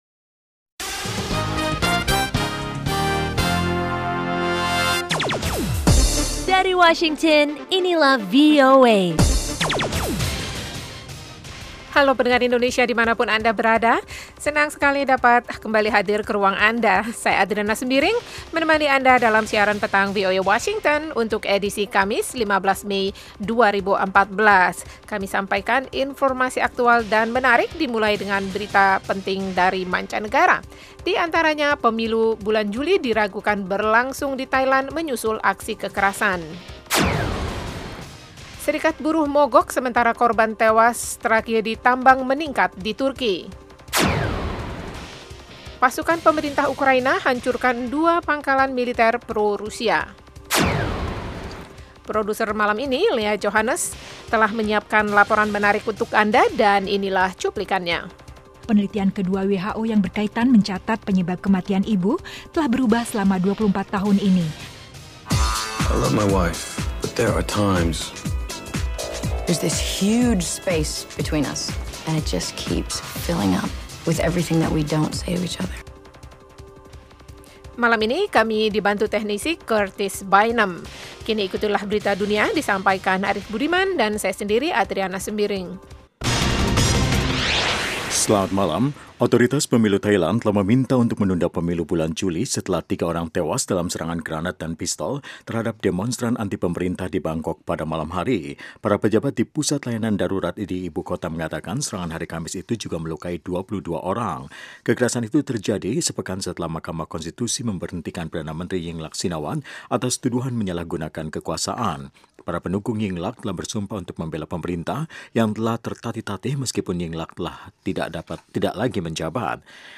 Selain padat dengan informasi, program ini sepanjang minggu menyuguhkan acara yang bernuansa interaktif dan penuh hiburan.
Kami menyajikan berbagai liputan termasuk mengenai politik, ekonomi, pendidikan, sains dan teknologi, Islam dan seputar Amerika. Ada pula acara musik lewat suguhan Top Hits, music jazz dan country.